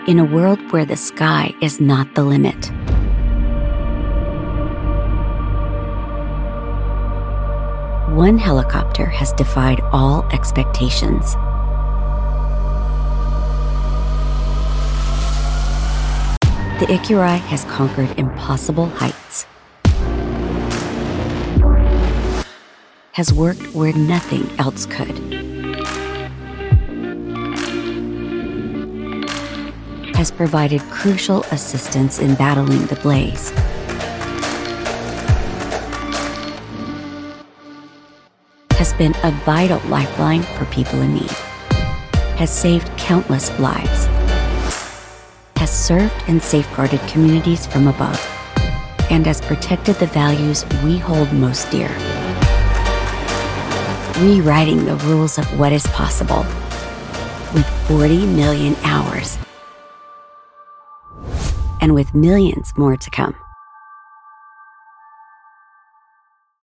Inglês (dos Estados Unidos)
Demonstração Comercial
Automotivo
Minha voz é coloquial, natural, crível, calorosa, jovem e, acima de tudo, real.
Meu estúdio de gravação é profissional, então sua gravação é clara, natural e limpa, sem ruídos interferentes.